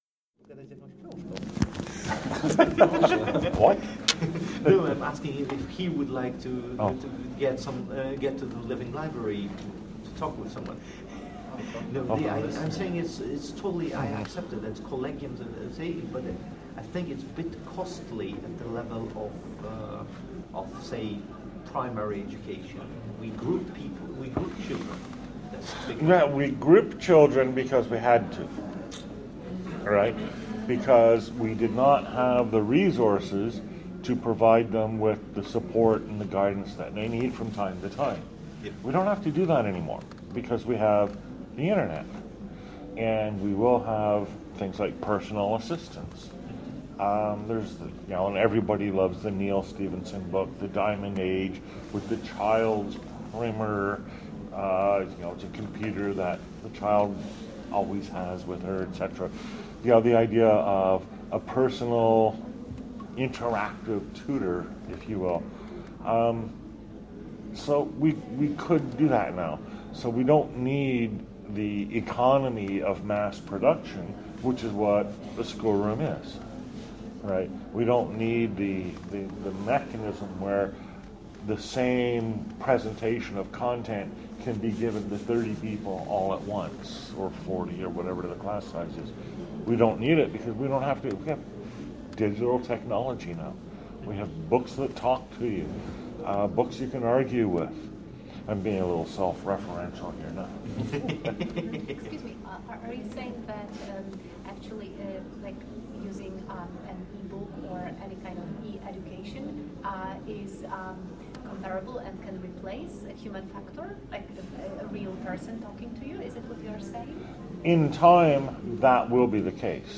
Very informal session in the 'Living Library' format (my first such) in which people dropped in and out to ask one or a few questions of me. Topics ranged all over the map.